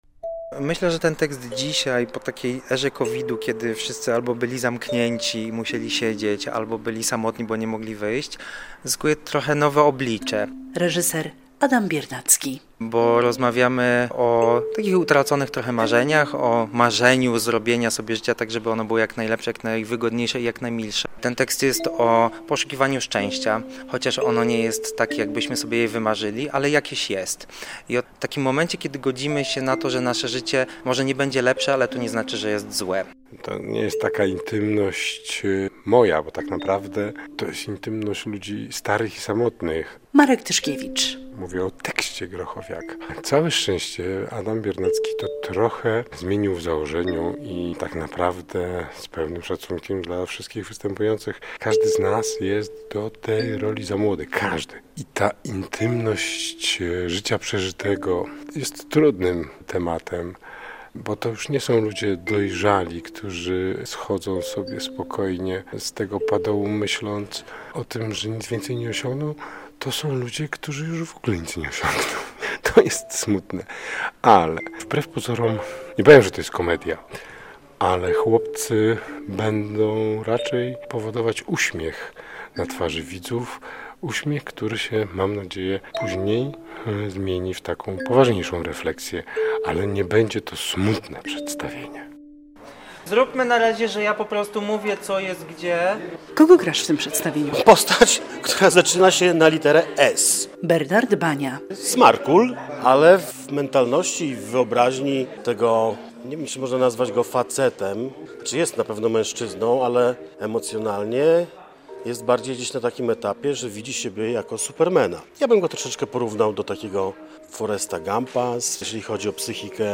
"Chłopcy" w Teatrze Dramatycznym na rozpoczęcie sezonu - relacja